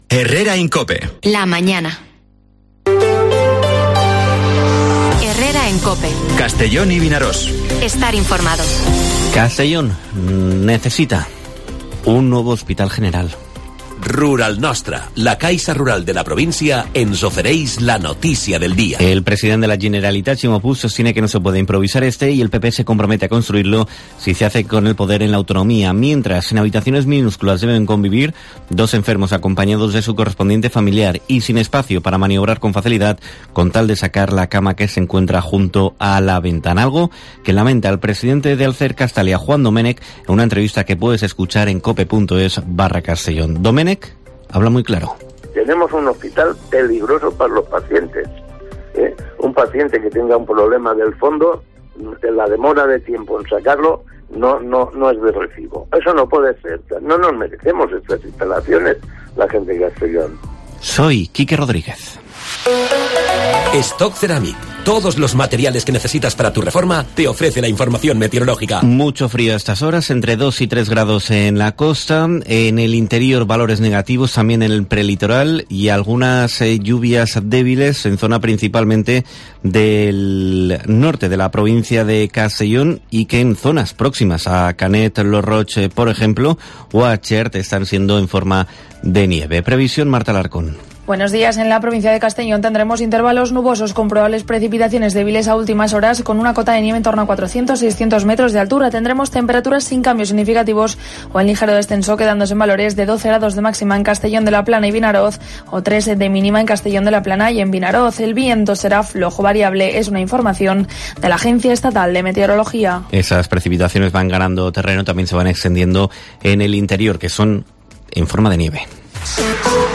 Informativo Herrera en COPE en la provincia de Castellón (24/01/2023)